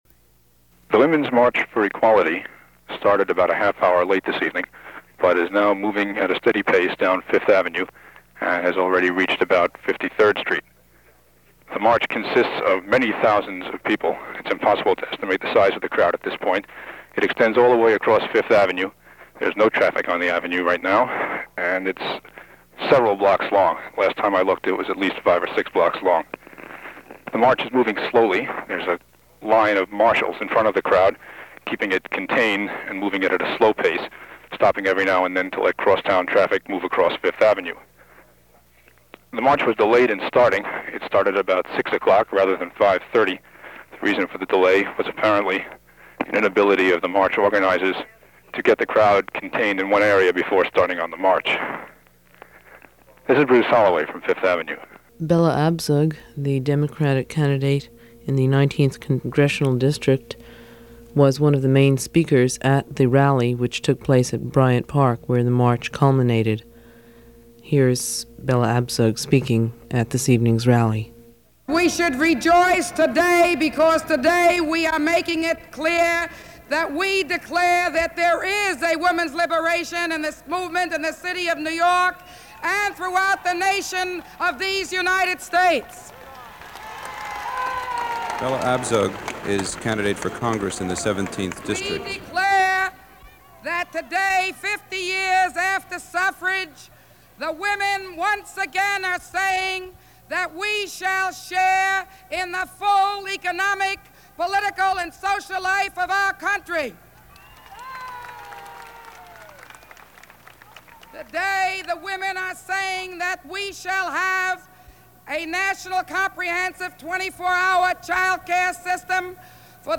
WBAI-FM recap